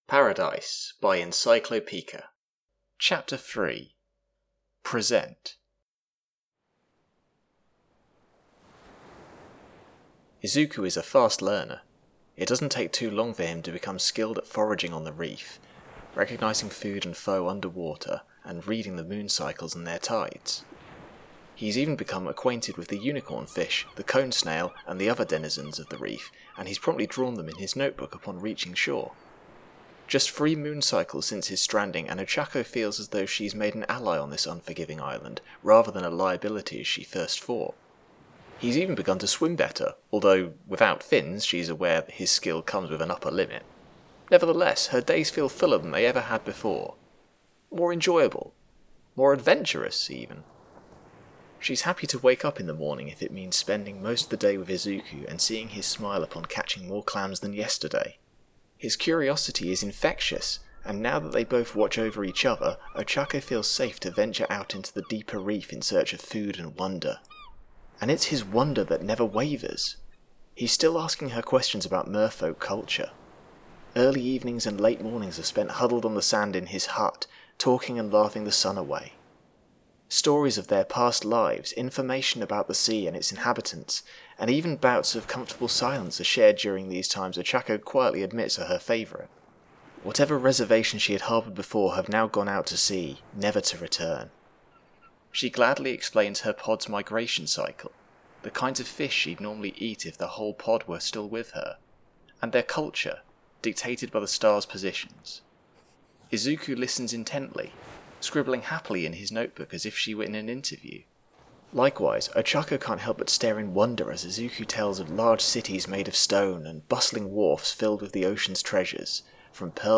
Voice of Izuku Midoriya
Voice of Ochako Uraraka
underwater ambience
" Heavy Rain " by lebaston100 This sound is licensed under CC BY 3.0 . " Thunder, Very Close, Rain, A.wav " by InspectorJ This sound is licensed under CC BY 3.0 .